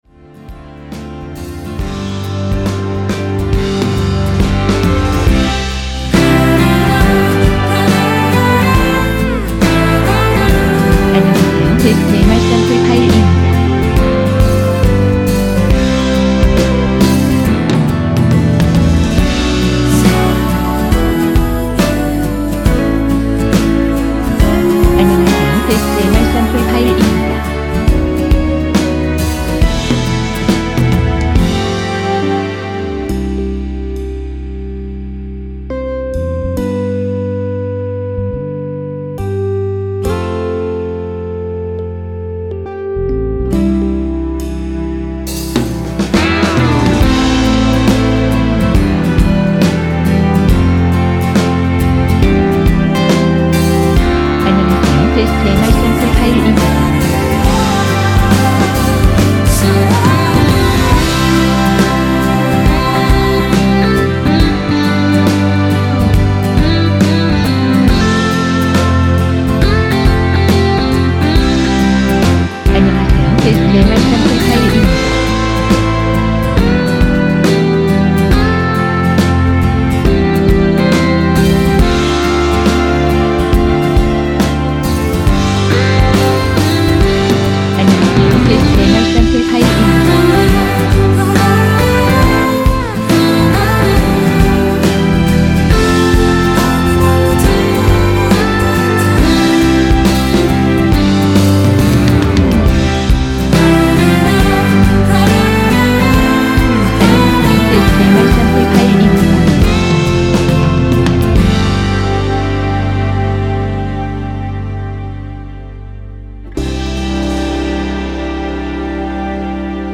전주 없이 시작하는 곡이라 전주 1마디 만들어 놓았습니다.(일반 MR 미리듣기 참조)
미리듣기에 나오는 부분이 코러스 추가된 부분 입니다.(미리듣기 샘플 참조)
원키에서(-1)내린 코러스 포함된 MR입니다.
앞부분30초, 뒷부분30초씩 편집해서 올려 드리고 있습니다.